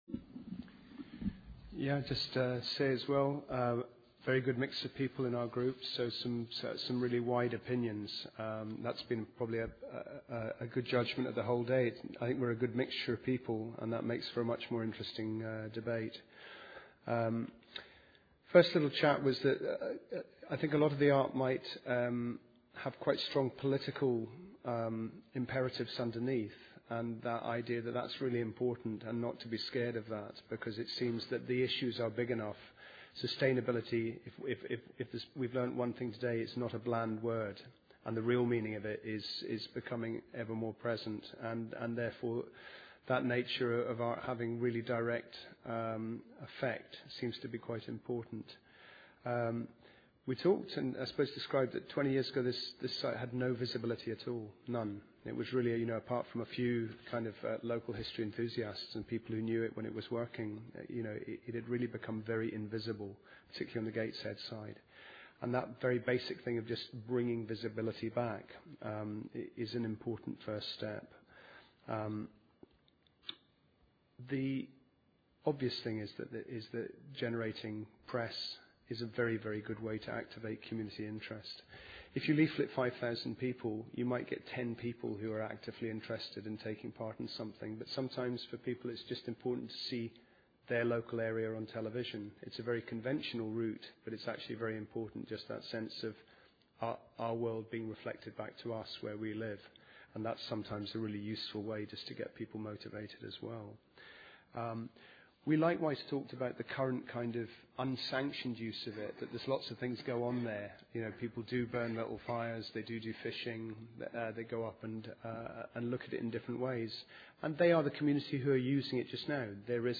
Click on the link to hear the audio of the feedback from each session by the workshop leaders.